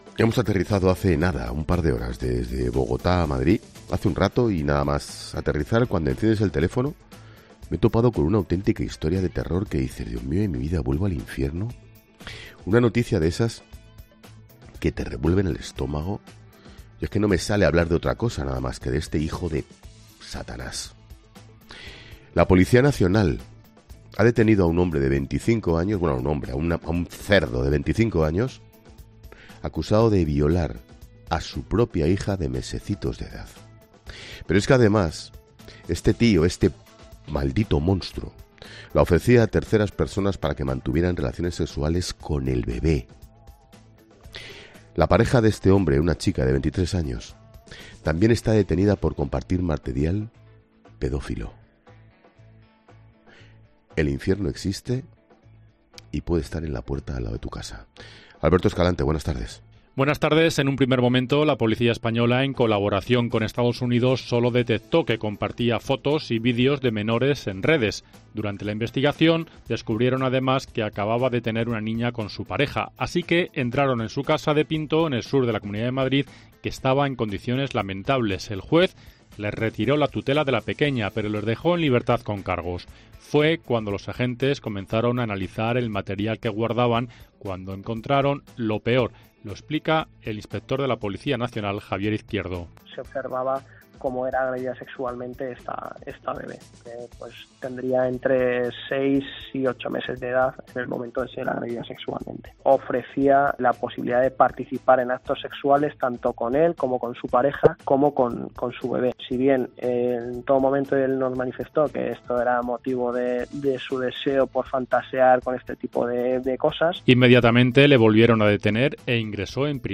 El director de La Linterna, Ángel Expósito, no daba crédito este martes en directo en el programa tras leer la noticia de los padres detenidos en el barrio madrileño de Aluche.
Pero es que además este maldito monstruo la ofrecía a terceras personas para que mantuvieran relaciones sexuales con el bebé”, añadía visiblemente enfadado Expósito en los micrófonos de La Linterna.